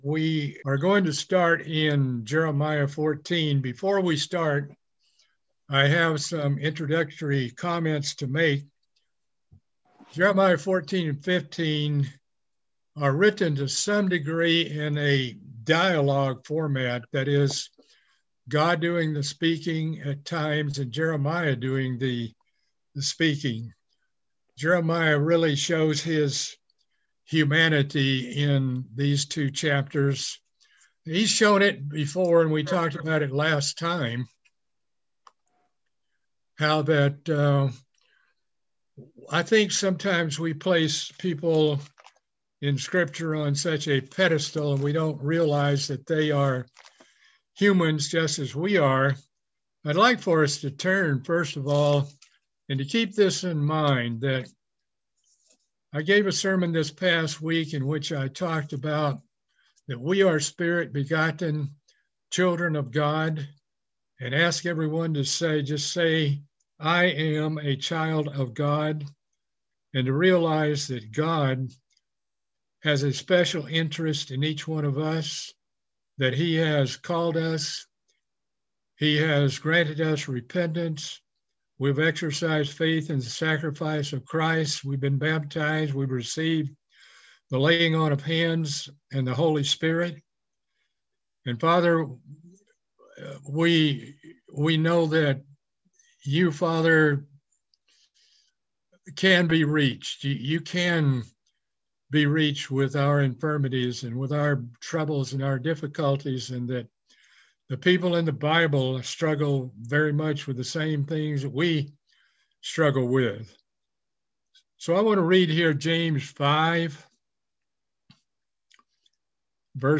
Part 9 of a Bible Study series on the book of Jeremiah.